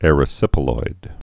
(ĕrĭ-sĭpə-loid, îrə-)